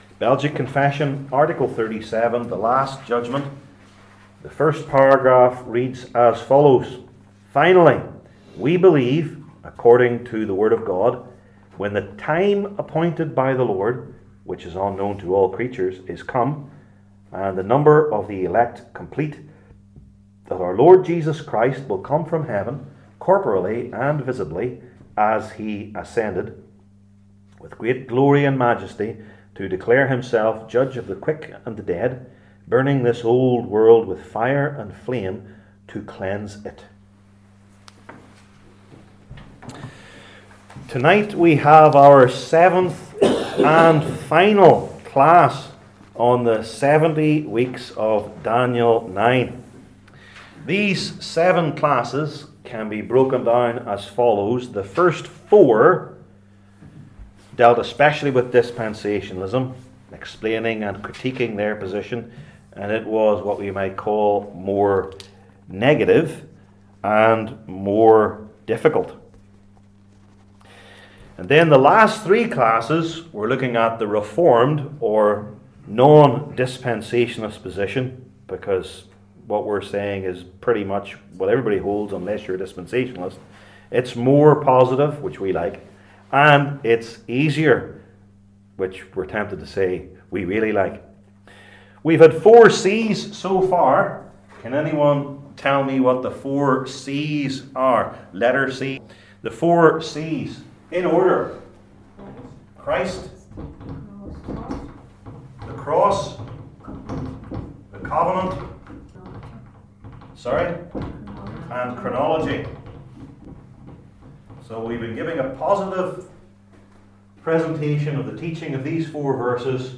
Passage: Daniel 9:20-27 Service Type: Belgic Confession Classes